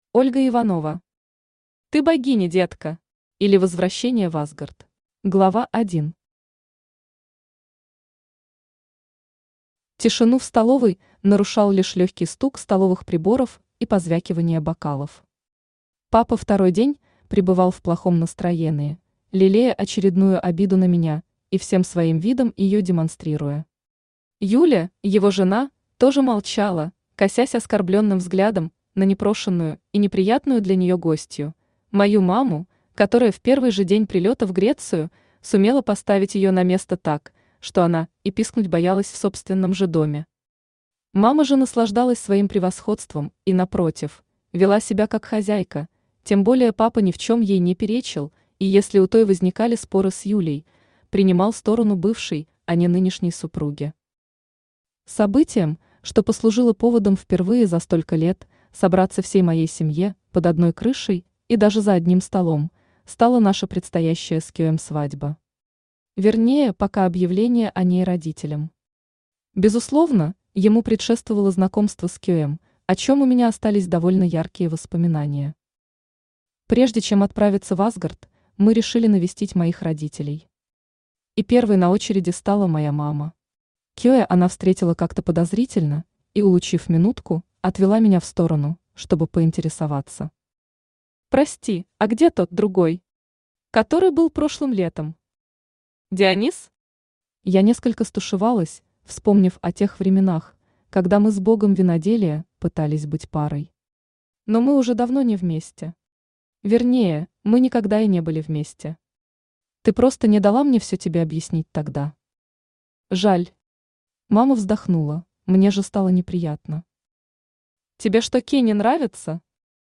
Аудиокнига Ты богиня, детка! или Возвращение в Асгард | Библиотека аудиокниг
Aудиокнига Ты богиня, детка! или Возвращение в Асгард Автор Ольга Дмитриевна Иванова Читает аудиокнигу Авточтец ЛитРес.